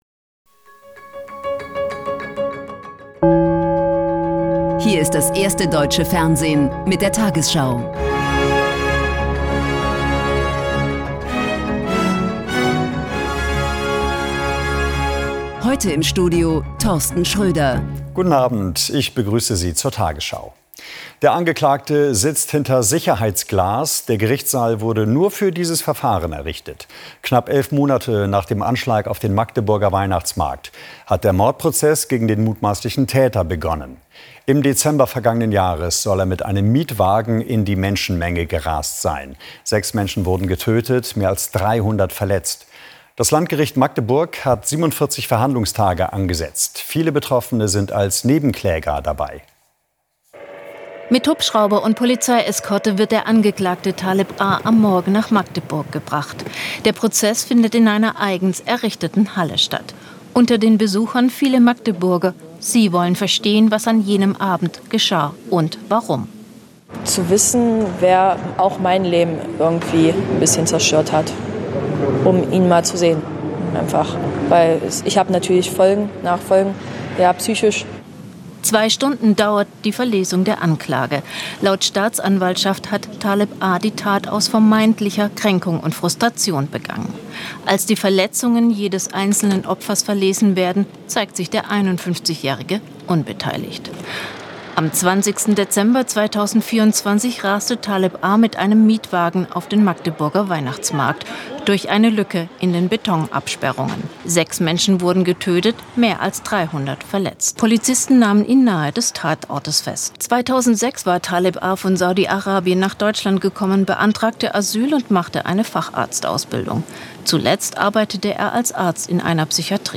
tagesschau 20:00 Uhr, 10.11.2025 ~ tagesschau: Die 20 Uhr Nachrichten (Audio) Podcast